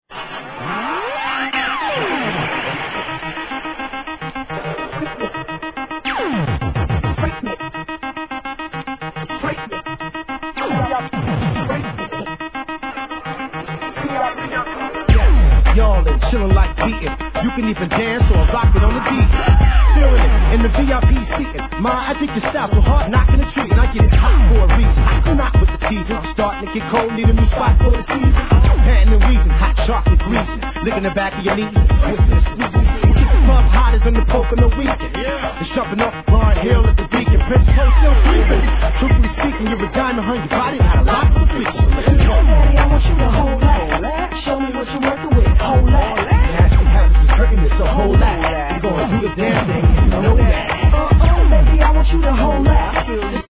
HIP HOP/R&B
ファミコンを思わせるような電子音炸裂がたまらない！！